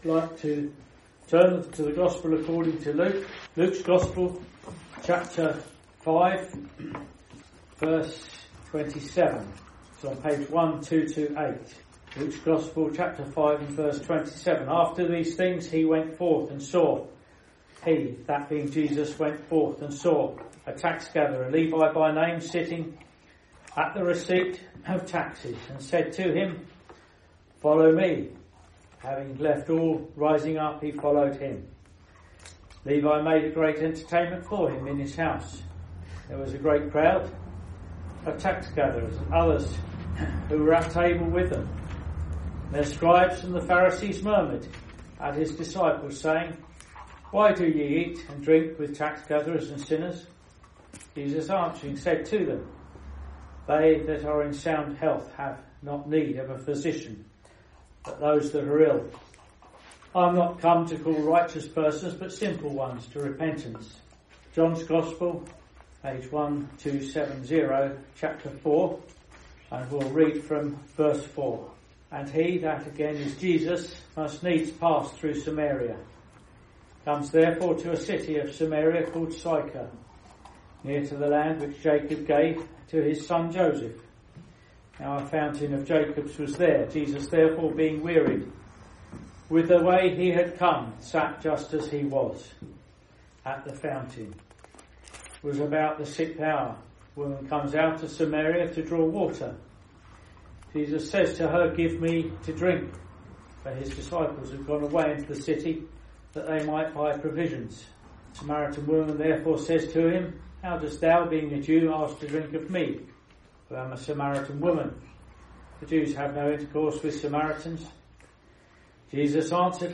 In this Gospel preaching, you will hear of three people who were called by Jesus. Each came to know Jesus personally as a result of the Lord drawing near and presenting the Gospel message.